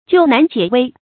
救難解危 注音： ㄐㄧㄨˋ ㄣㄢˋ ㄐㄧㄝ ˇ ㄨㄟ 讀音讀法： 意思解釋： 幫助陷入困境的人解除危難。